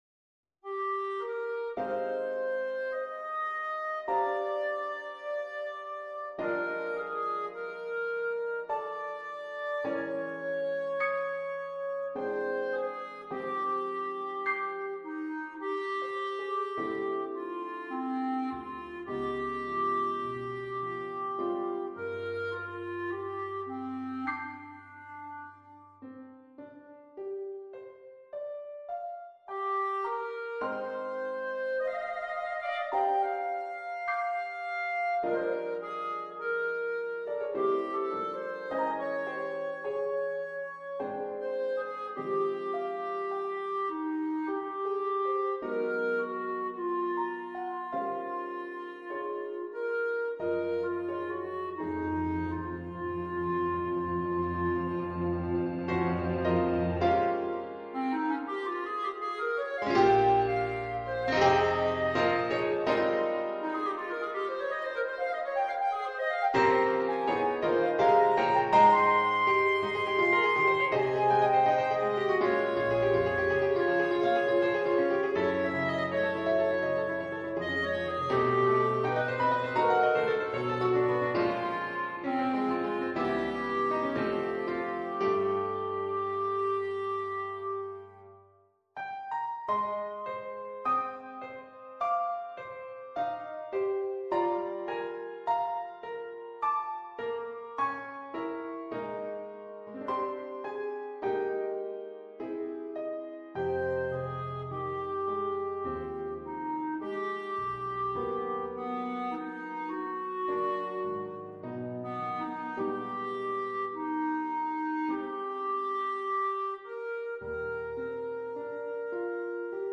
for clarinet en piano